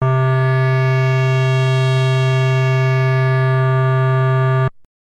Here ENV1 is played alone (Op=Sr2):